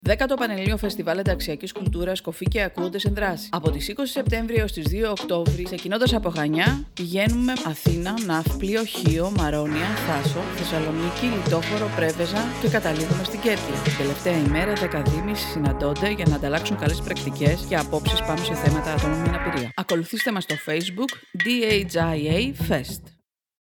Έτοιμο και το ραδιοφωνικό σποτ του φεστιβάλ